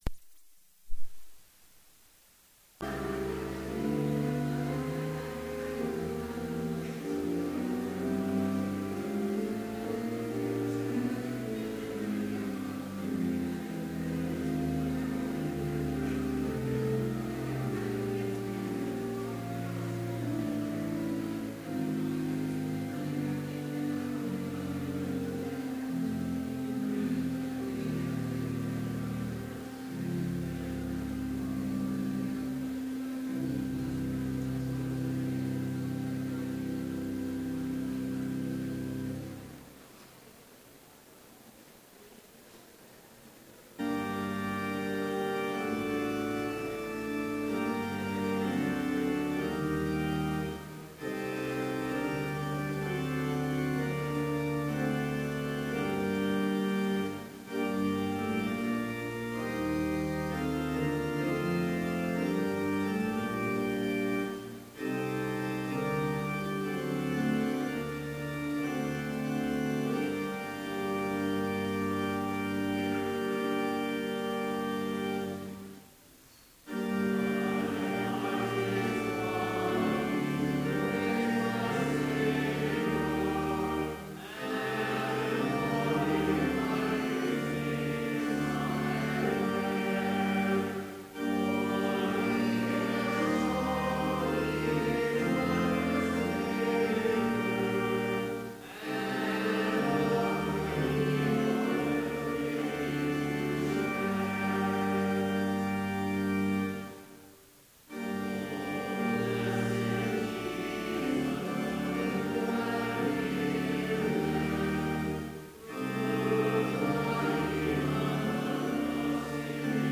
Complete service audio for Chapel - November 24, 2015